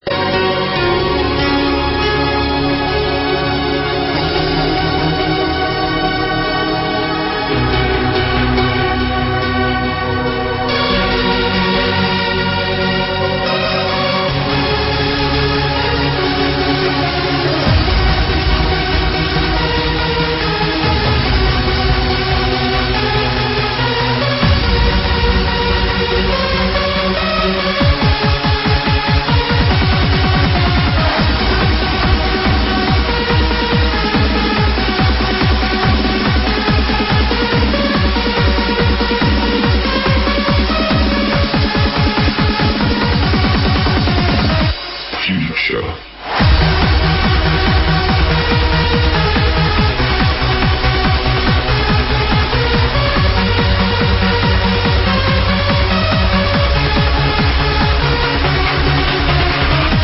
Help ID an awesome Trance Song